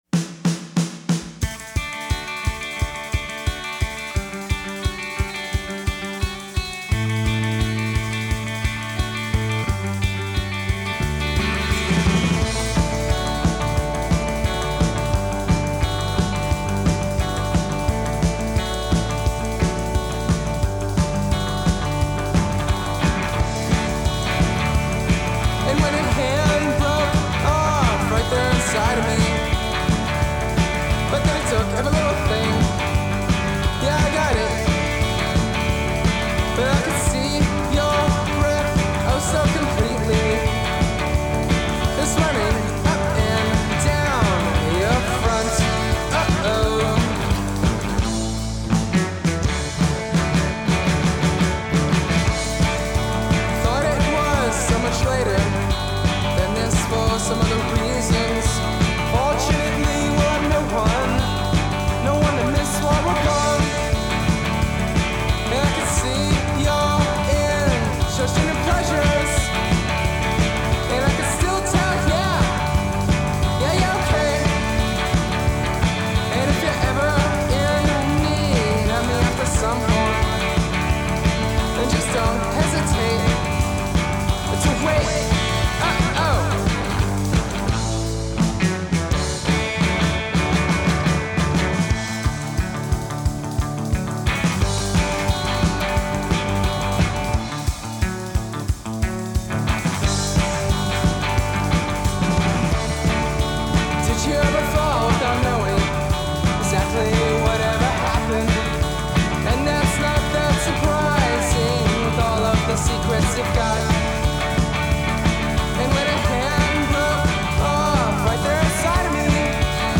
Classico Pop Anni Ottanta
Sette canzoni tutte sotto i tre minuti